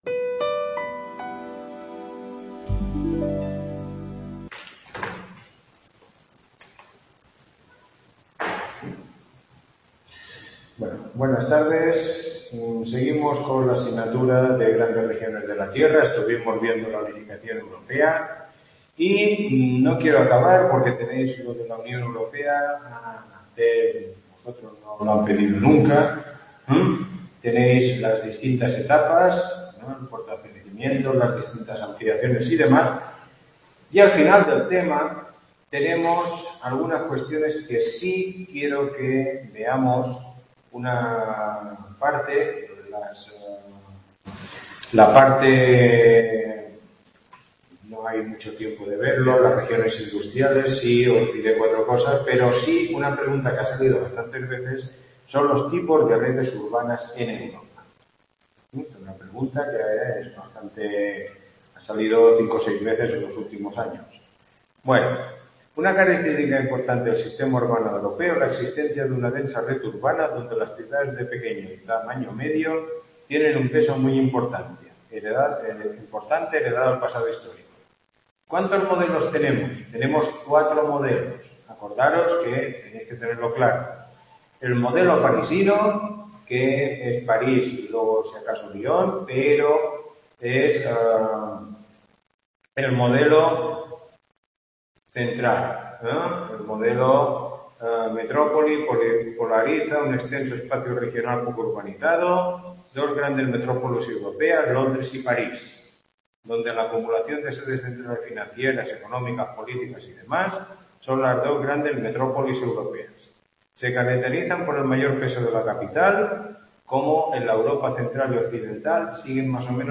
Tutoría 3